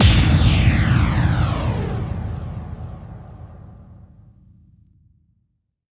VEC3 FX Reverbkicks 24.wav